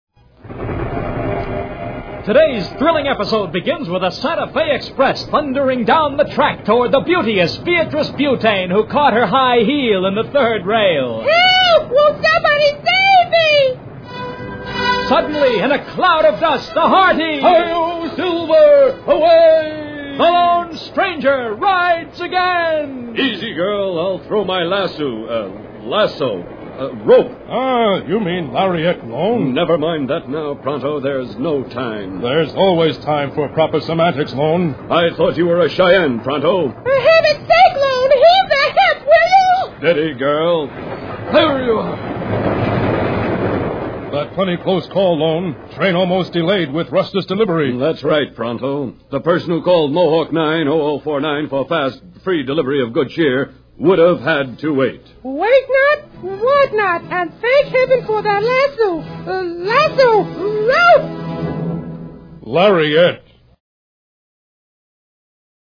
AF Rustice commercial 1